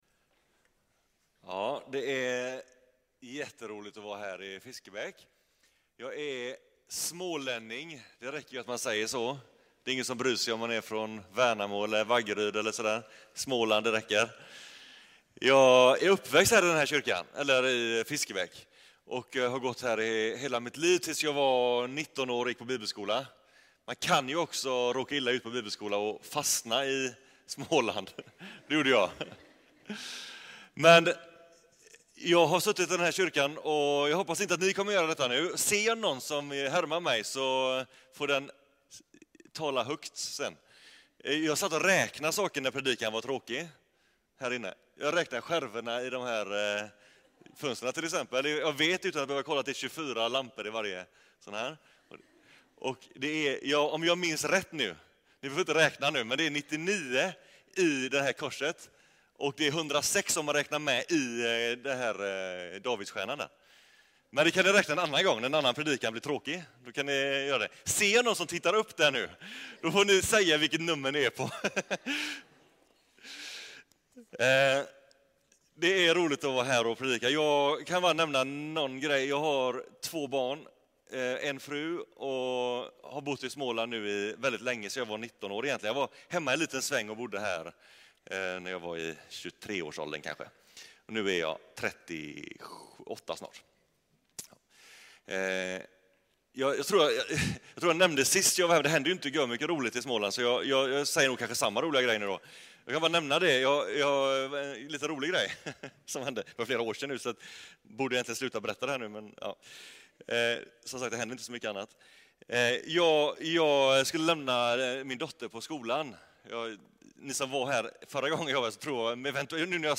Unga vuxna-gudstjänst